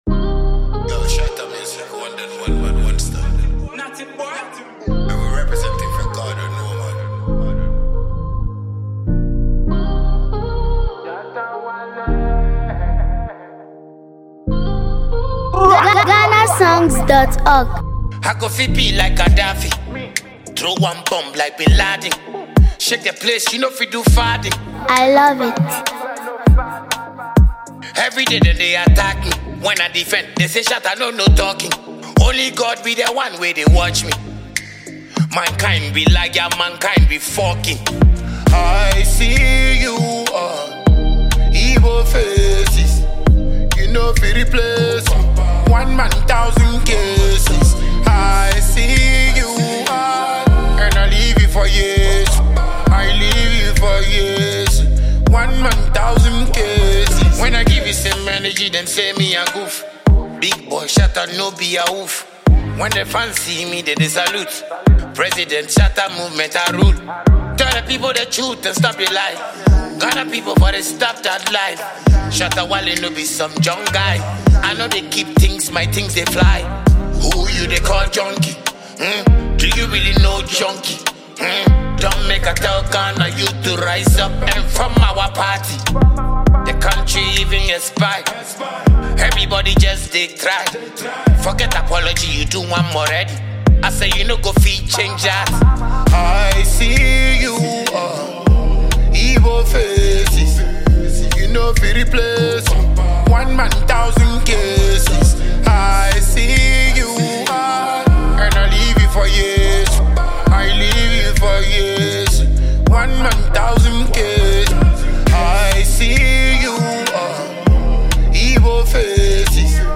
Dancehall
With raw lyrics and emotional delivery